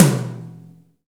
Index of /90_sSampleCDs/Roland L-CDX-01/KIT_Drum Kits 5/KIT_Induced Kit
TOM GRINDE07.wav